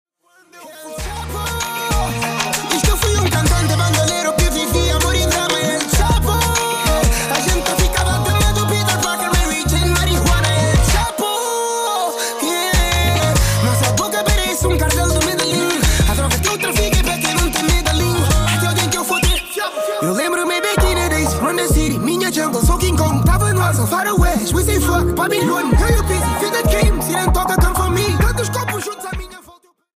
Hip-Hop / Urban